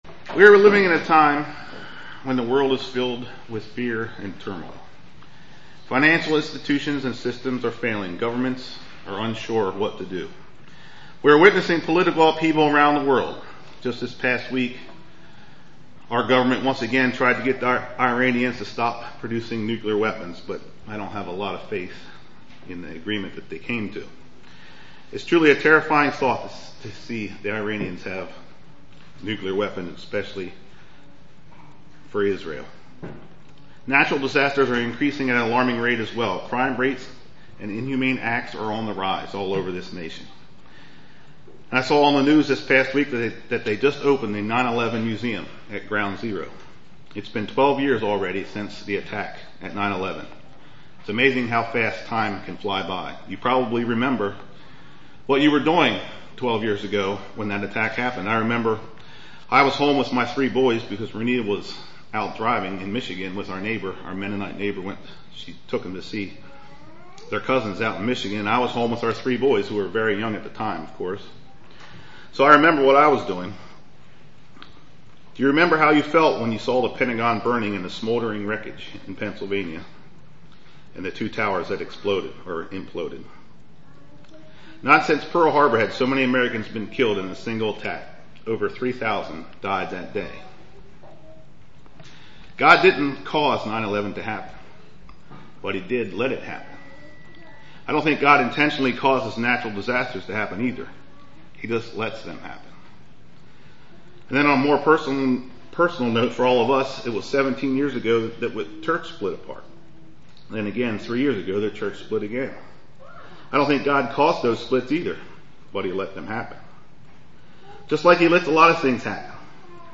Given in Lewistown, PA
UCG Sermon Studying the bible?